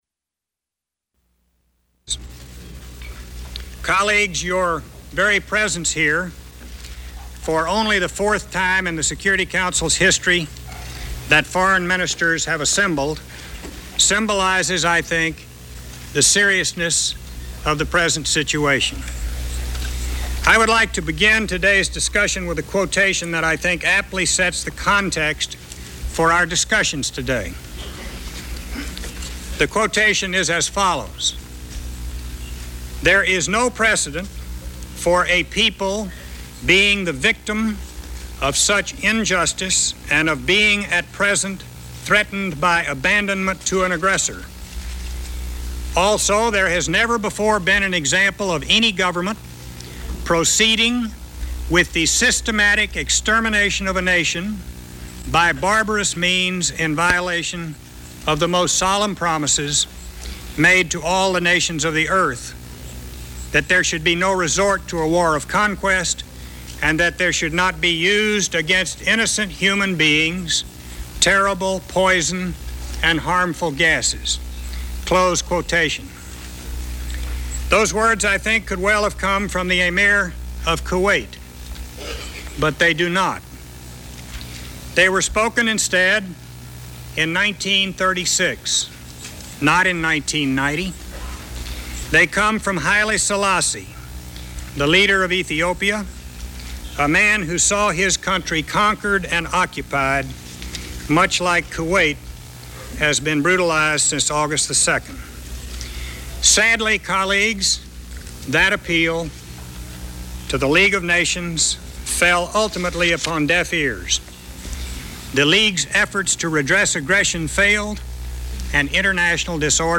Secretary of State James Baker addresses the United Nations before the vote on military sanctions against Iraq